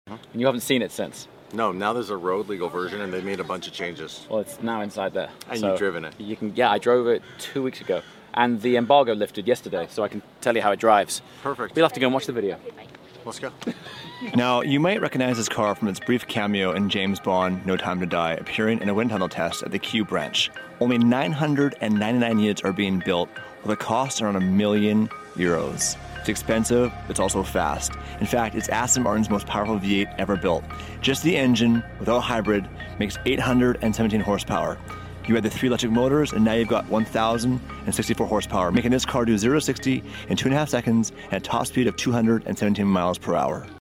Aston Martin's NEW Supercar_ Fast sound effects free download